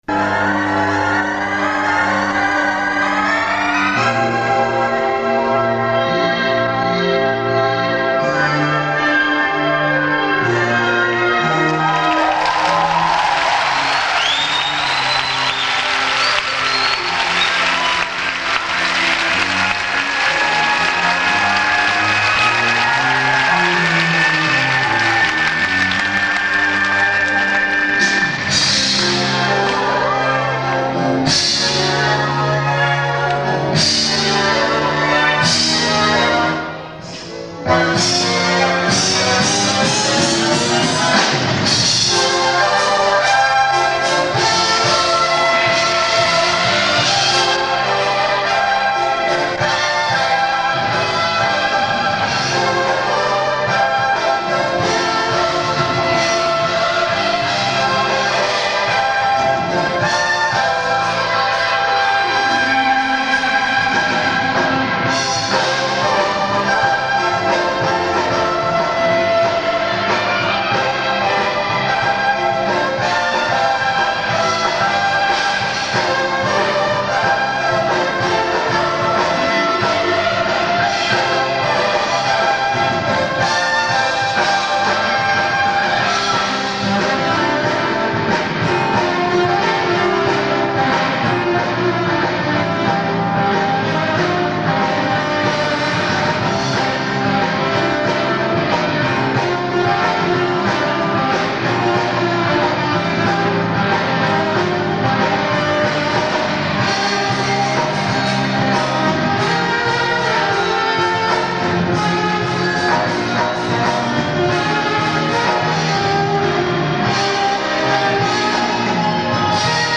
1994년 내한공연